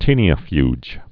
(tēnē-ə-fyj)